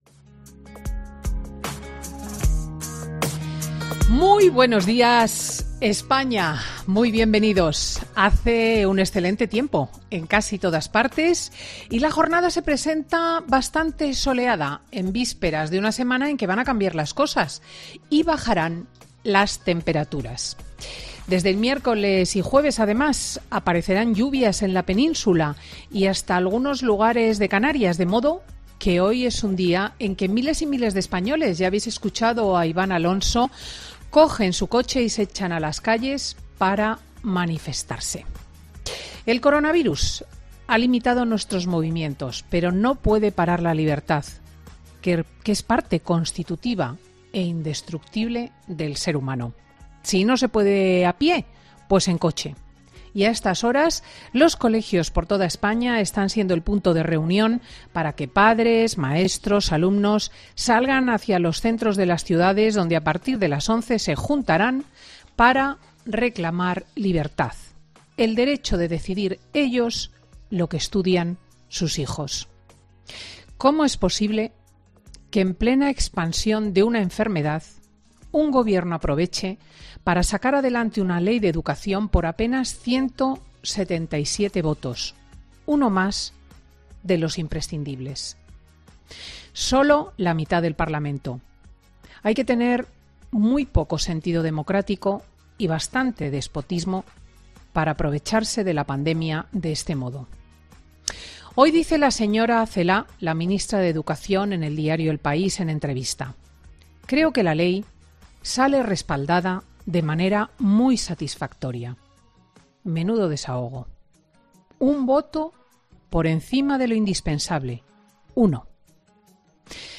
La actualidad de la jornada en el editoria de Cristina de este domingo